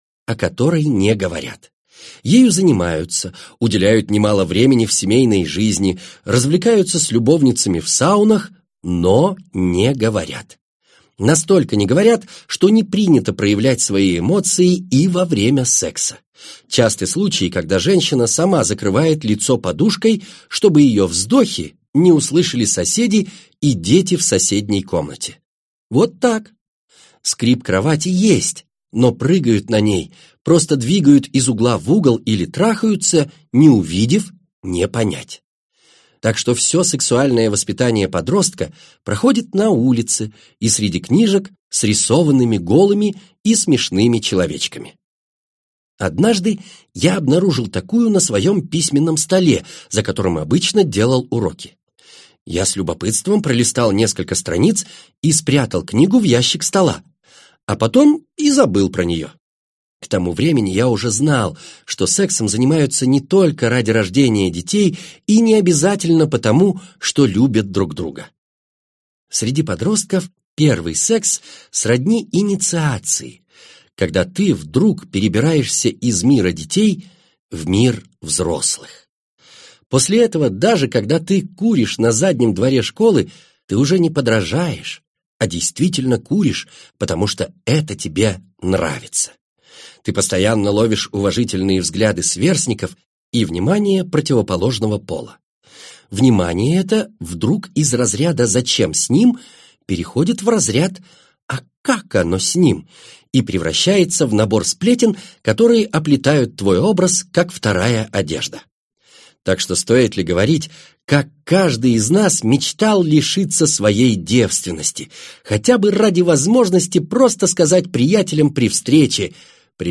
Аудиокнига Исповедь zadrota | Библиотека аудиокниг